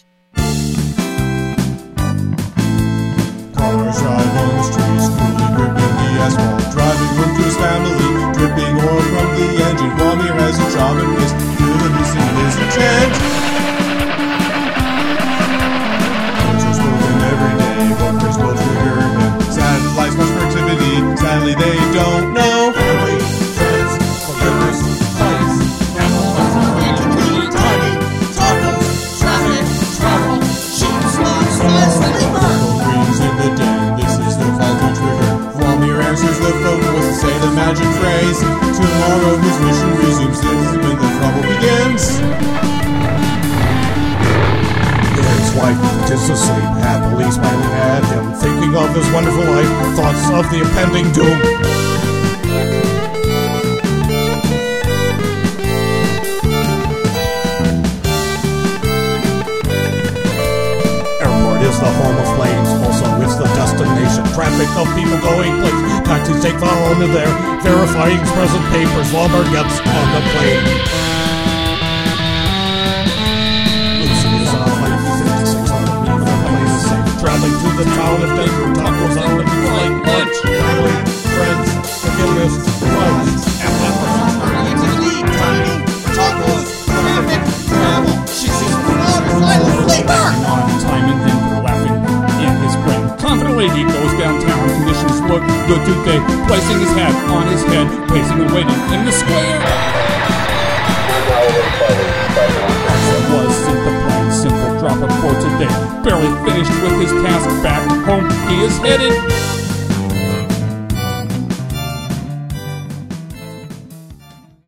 It's that absurd keyboard crashing that does it for me. And the feamle backing vocals. Apparently random guitar solos and motorbike noises are great as well.